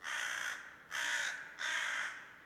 crow_one.ogg